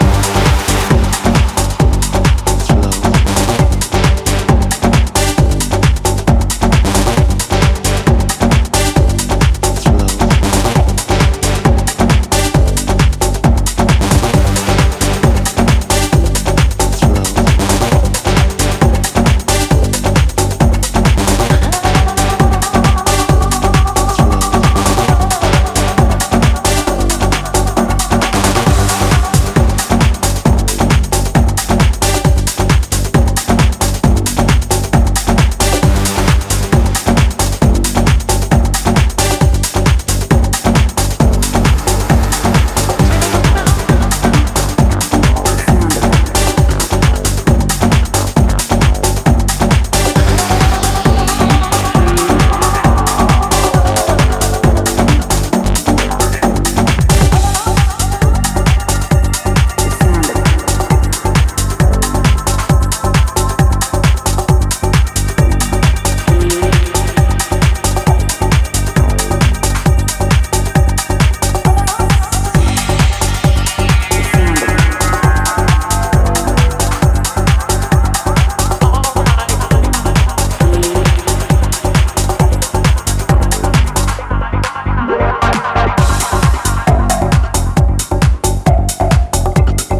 様々なハウス現場のピークタイムにハマりそうなトライバル・グルーヴ